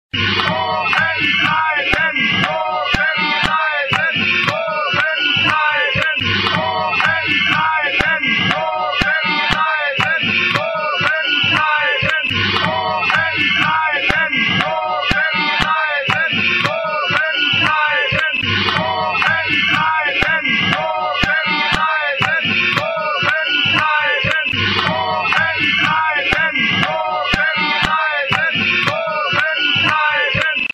Oben-Bleiben Klingelton Version 2 (hohe Töne abgedämpft)